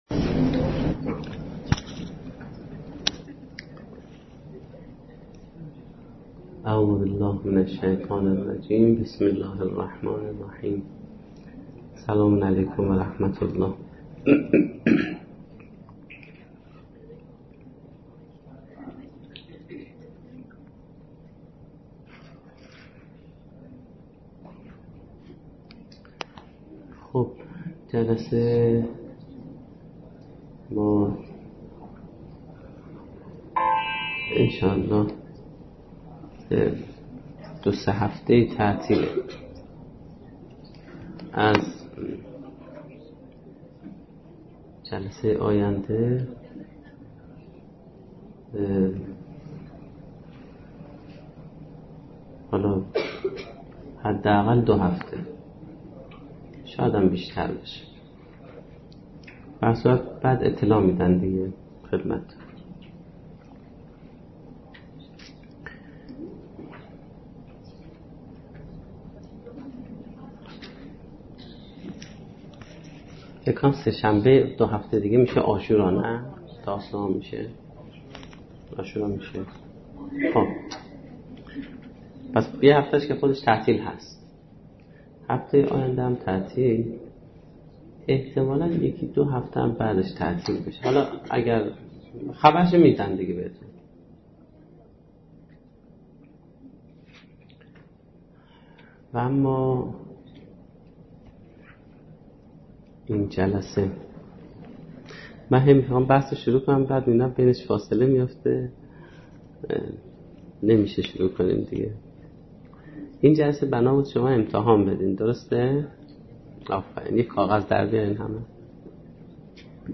سخنرانی
در دانشگاه فردوسی با موضوع طراحی سرنوشت